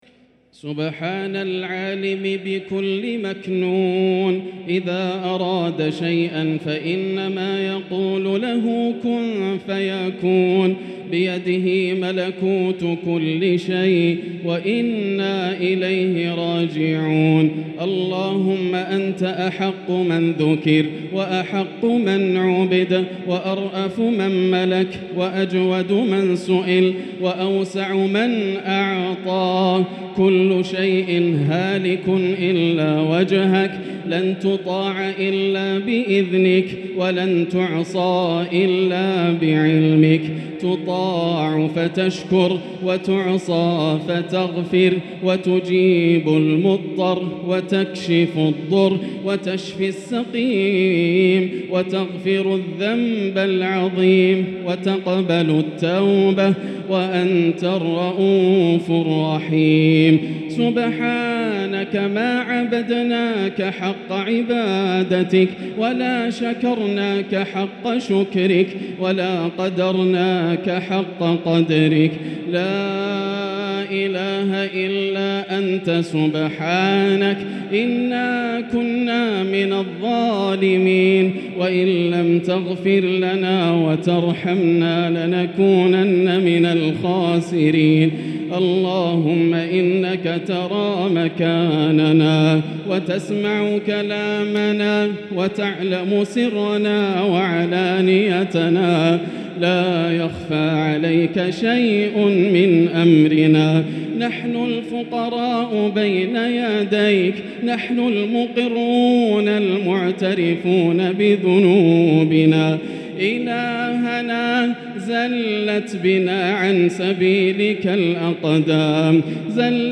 دعاء القنوت ليلة 18 رمضان 1444هـ | Dua 18 st night Ramadan 1444H > تراويح الحرم المكي عام 1444 🕋 > التراويح - تلاوات الحرمين